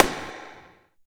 50 SNARE 3-R.wav